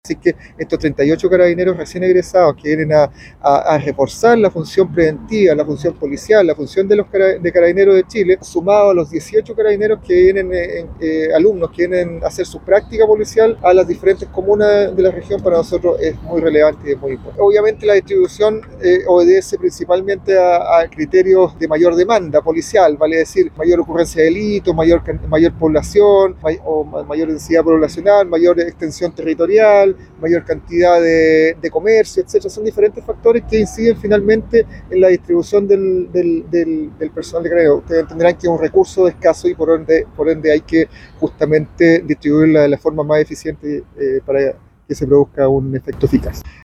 El general Valdés explicó que el personal será distribuido en distintas comunas de la zona, teniendo en cuenta criterios como la densidad poblacional, mayor ocurrencia de delitos y extensión territorial, entre otros.